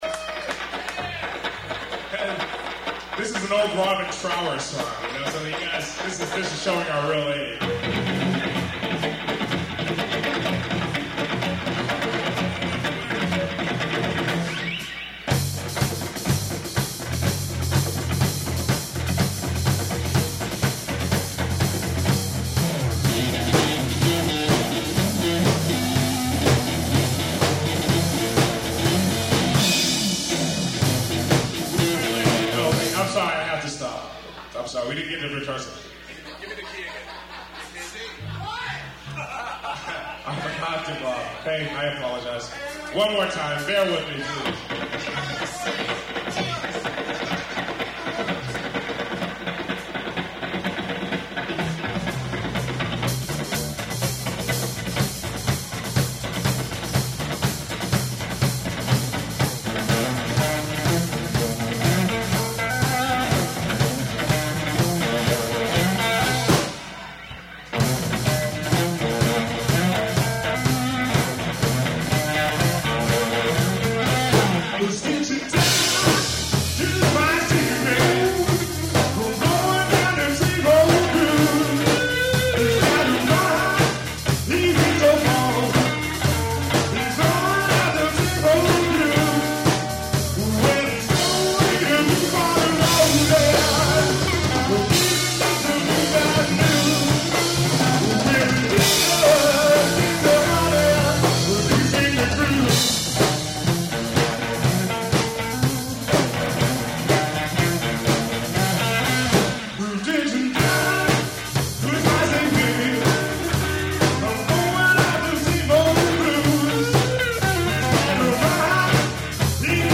slow blues